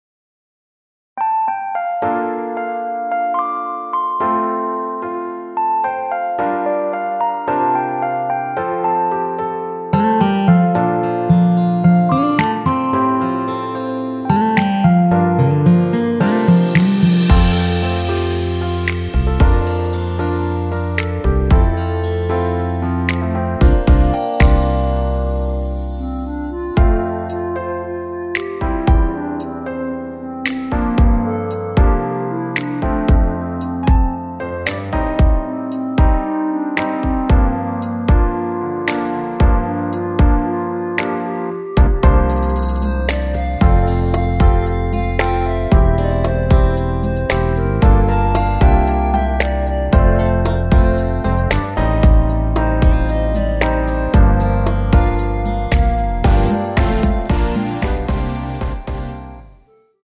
원키에서(-2)내린 멜로디 포함된 MR입니다.(미리듣기 확인)
멜로디 MR이라고 합니다.
앞부분30초, 뒷부분30초씩 편집해서 올려 드리고 있습니다.
중간에 음이 끈어지고 다시 나오는 이유는